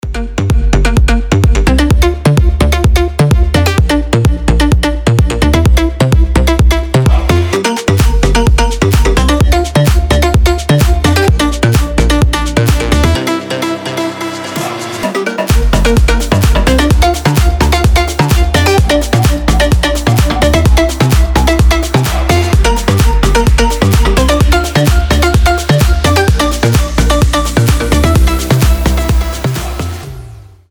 • Качество: 320, Stereo
ритмичные
dance
Electronic
EDM
звонкие
Стиль: future house